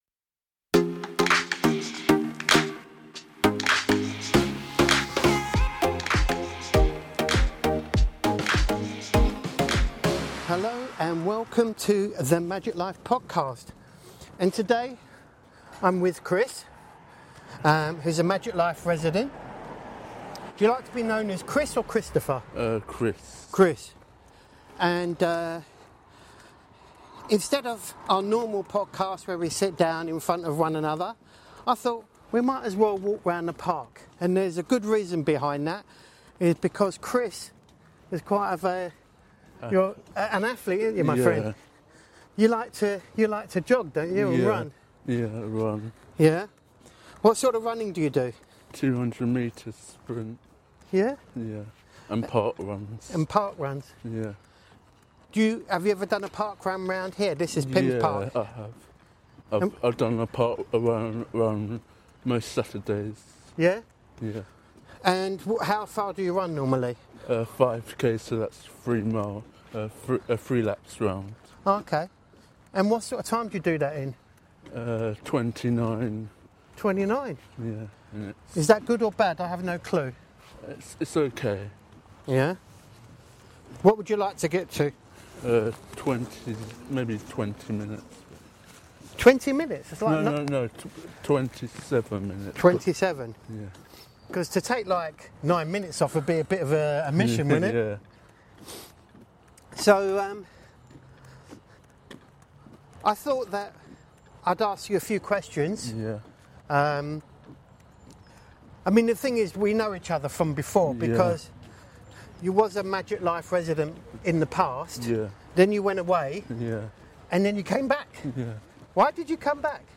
Walking side by side creates an honest, unfiltered conversation about real life, routines, challenges and ambitions.